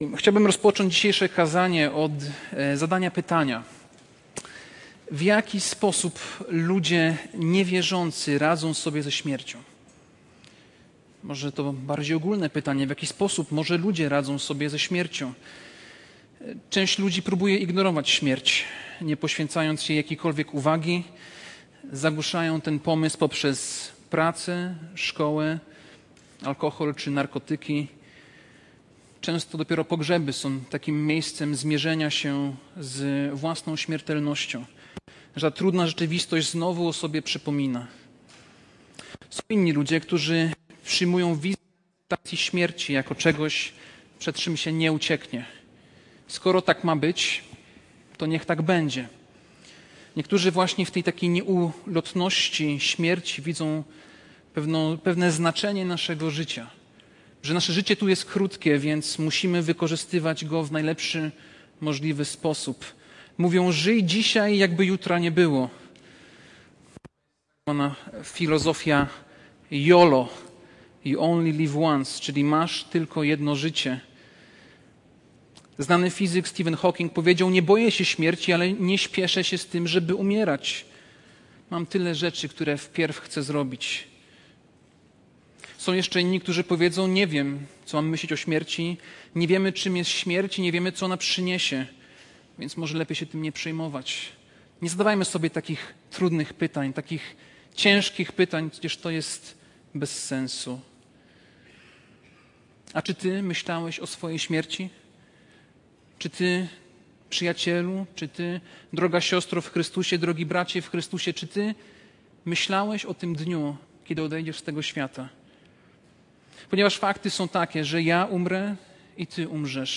Passage: List do Hebrajczyków 11, 20-22 Kazanie